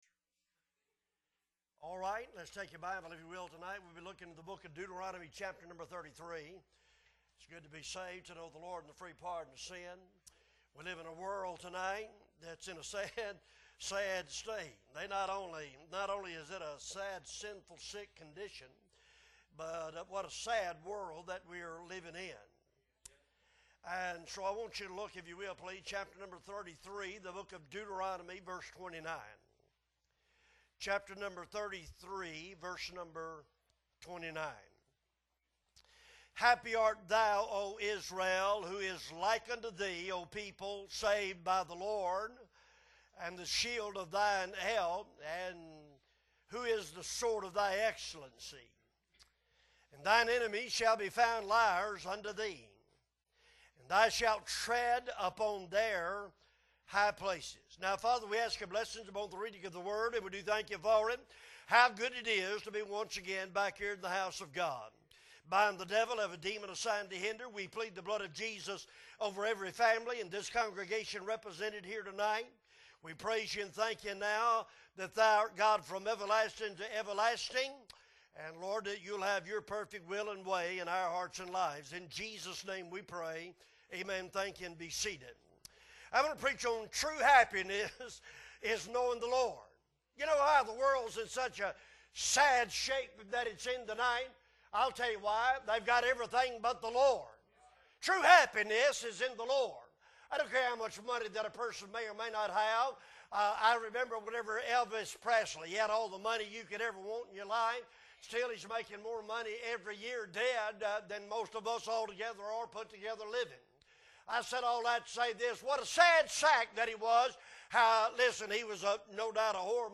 March 15, 2023 Wednesday Night Service - Appleby Baptist Church